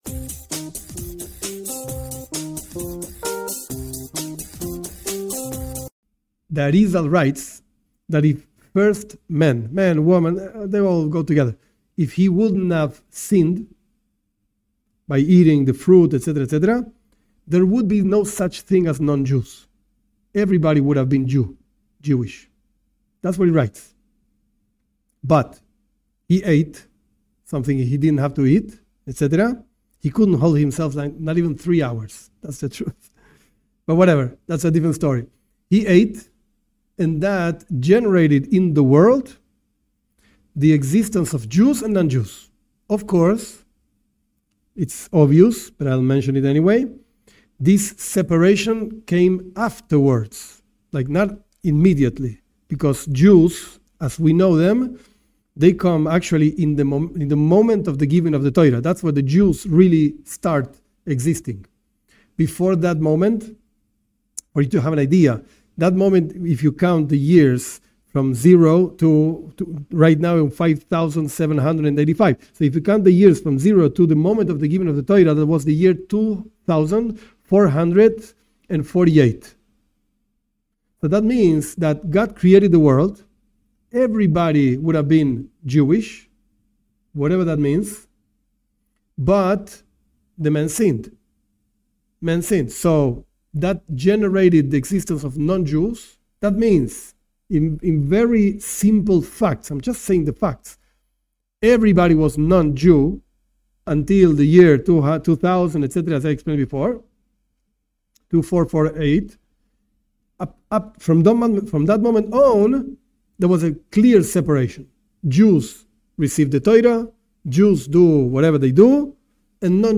Did God create only Jews? This part of a class tries to answer this question providing sources. When did non-Jews appear in creation? When did Jews come into history?